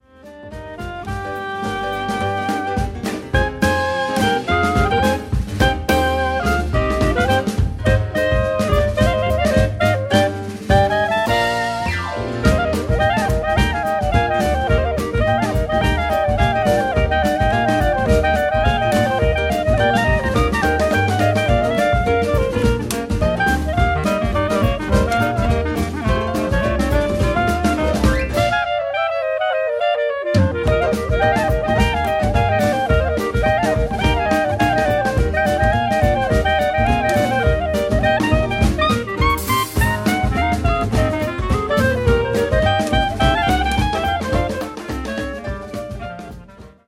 ranges from traditional tunes to Latin rhythms.